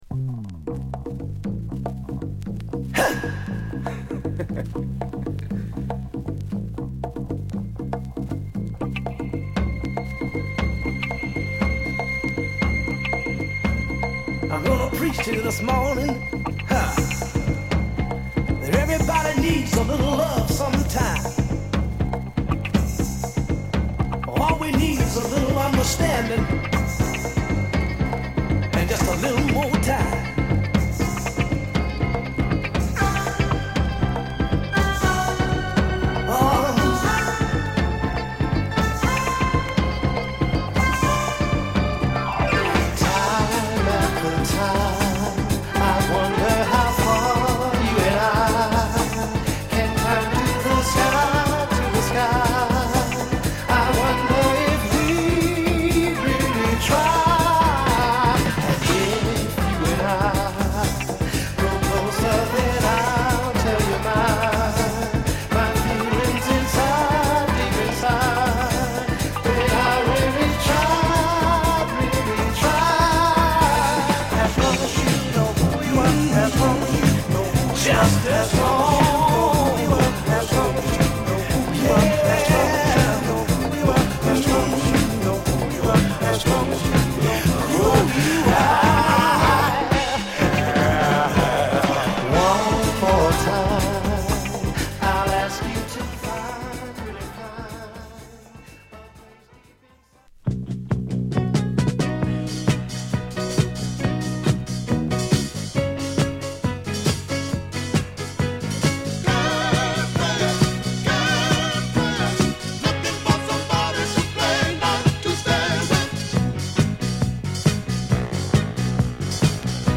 アルバム通して、フィリー〜N.Y.サウンドなダンストラックを満載した本作。